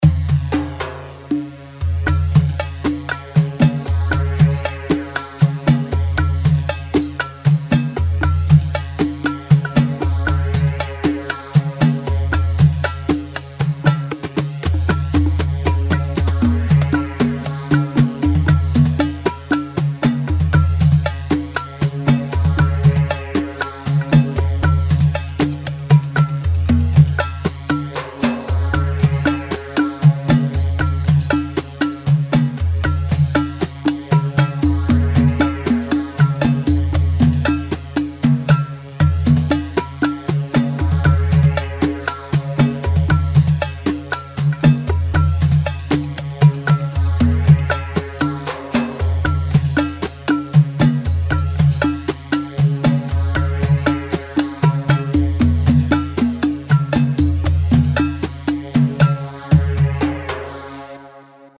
Rhumba 4/4 117 رومبا
Rhumba-4-4-117.mp3